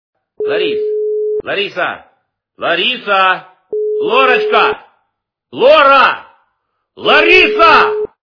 Именной звонок для Ларисы - Ларис, Лариса, Лариса, Лорочка, Лора, Лариса Звук Звуки Іменний дзвінок для Ларіси - Ларис, Лариса, Лариса, Лорочка, Лора, Лариса